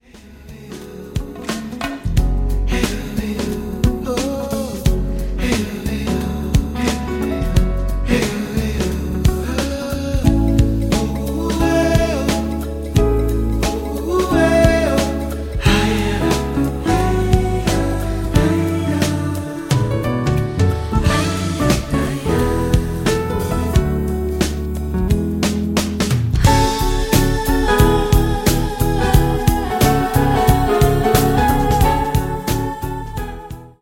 90s Jazz